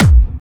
/audio/sounds/Vengeance Samples/VEC1 Bassdrums/VEC1 Clubby/
VEC1 BD Clubby 32.wav